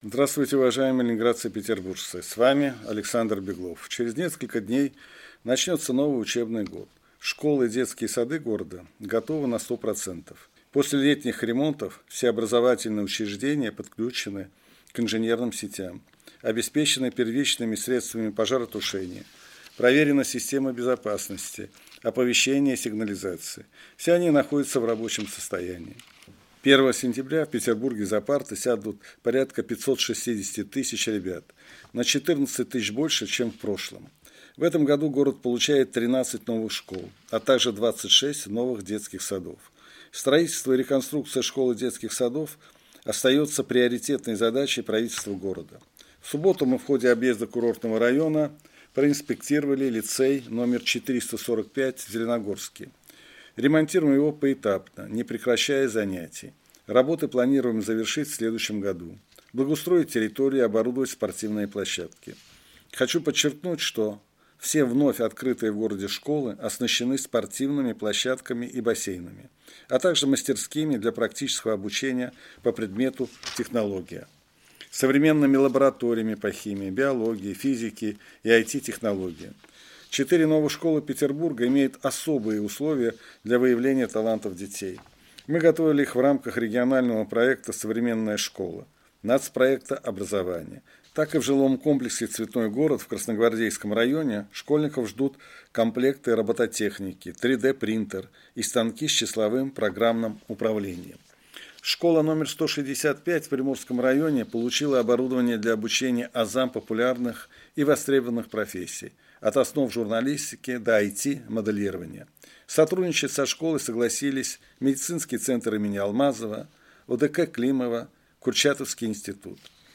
Эфир «Радио России — Санкт‑Петербург» от 29 августа 2022 года